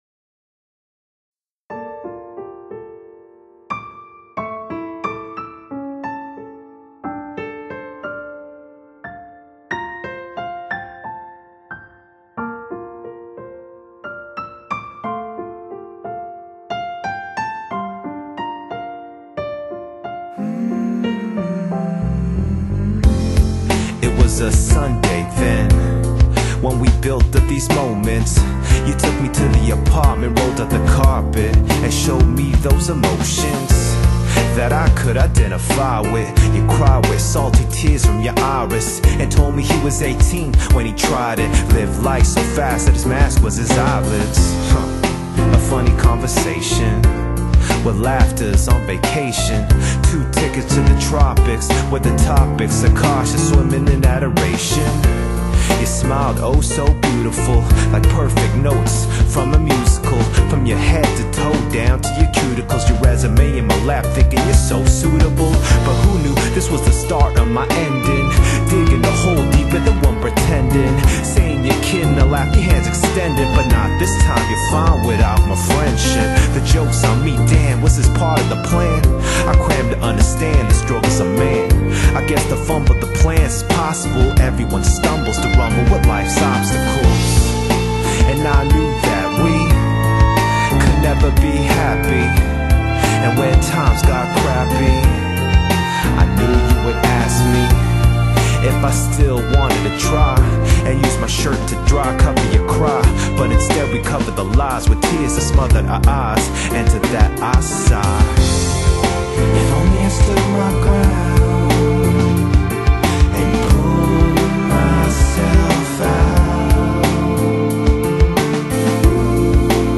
Genre: Hip-Hop, Jazz, BreakBeat, Classical
专辑在Hip-Hop的基础上融入了多种音乐元素，配乐弦律非常优美、唯美浪漫。